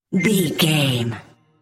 Sound Effects
Atonal
funny
magical
mystical